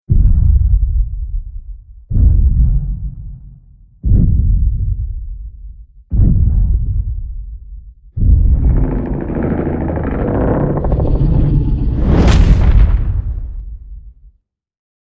Download Monster Footstep sound effect for free.
Monster Footstep